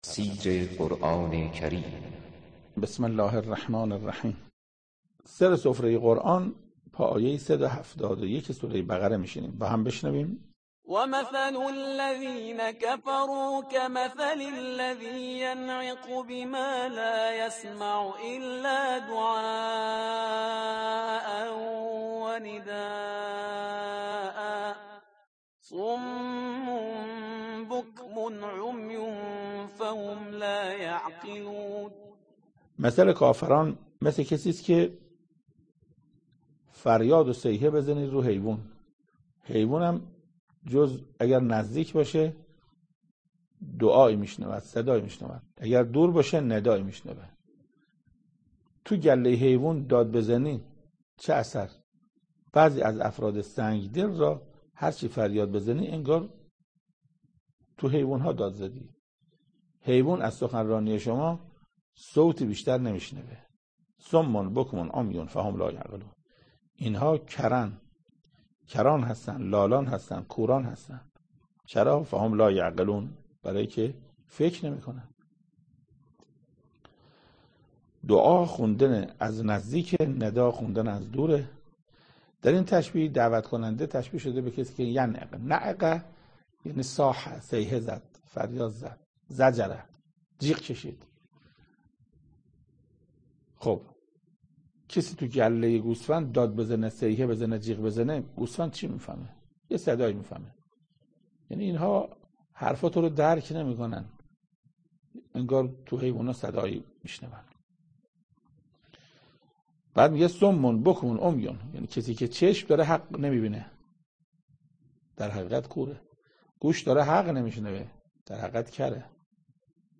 تفسیر صد و هفتاد و یکمین آیه از سوره مبارکه بقره توسط حجت الاسلام استاد محسن قرائتی به مدت 5 دقیقه